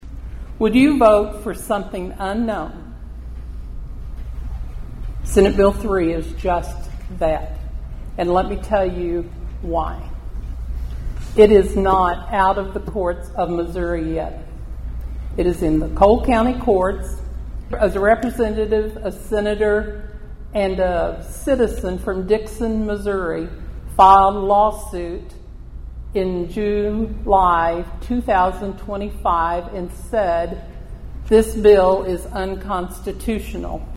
A Countywide Town Hall Meeting was held last night at the Thayer High School. The meeting was to talk about issues on the ballot in the upcoming election and to hear from the candidates.